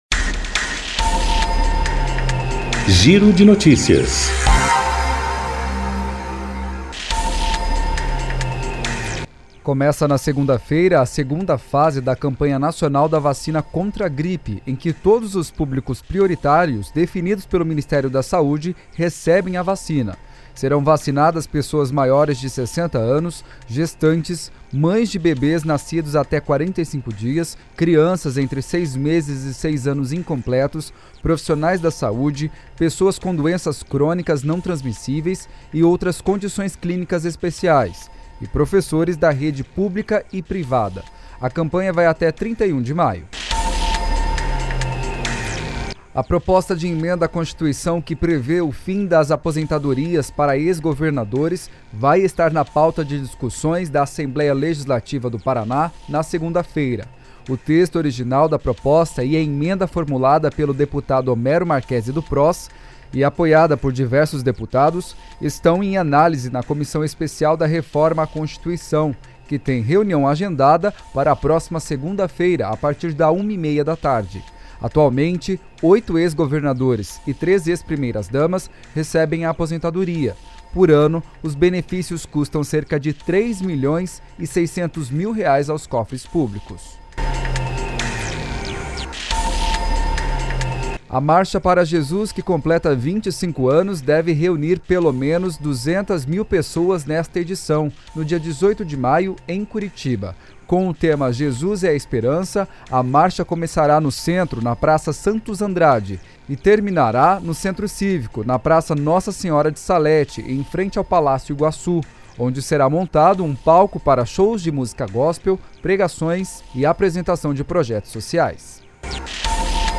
Giro de Notícias COM TRILHA